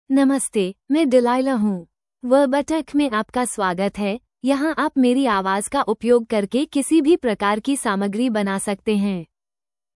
DelilahFemale Hindi AI voice
Delilah is a female AI voice for Hindi (India).
Voice sample
Listen to Delilah's female Hindi voice.
Female